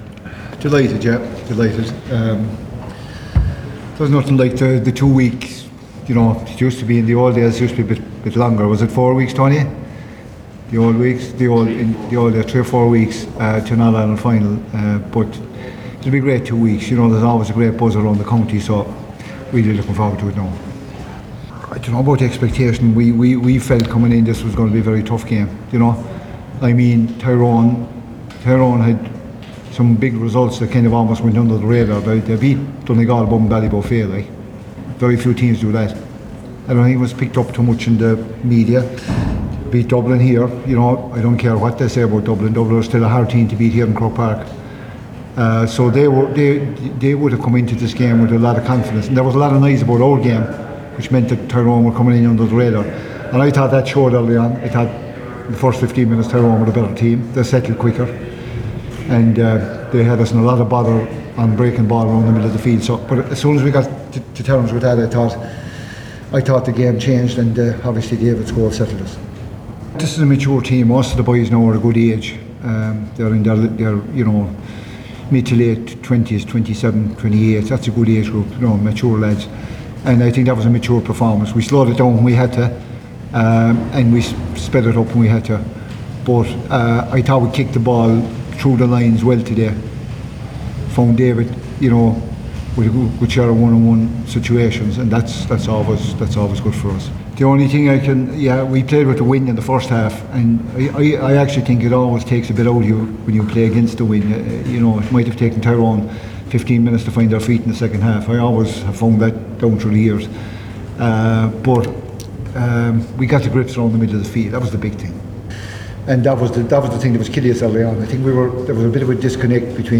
O’Connor spoke to the assembled media after the game and said he’s looking forward to the build up to the final…